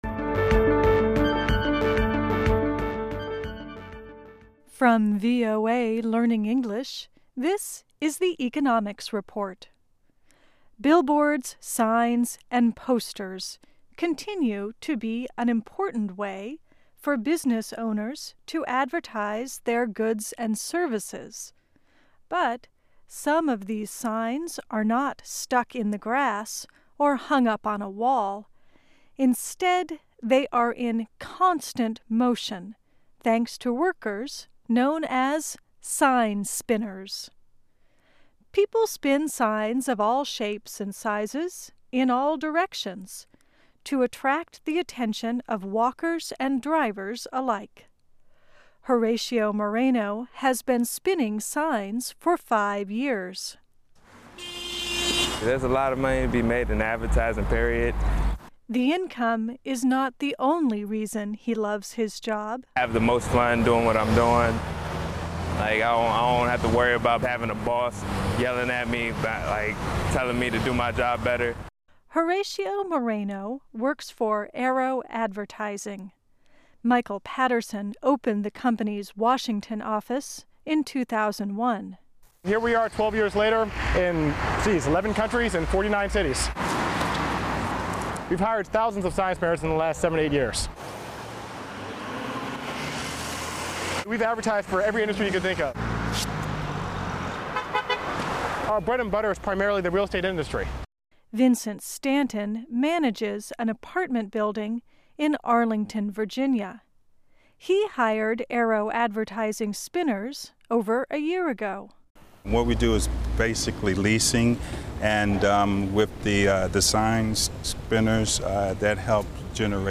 Learn English as you read and listen to news and feature stories about business, finance and economics. Our daily stories are written at the intermediate and upper-beginner level and are read one-third slower than regular VOA English.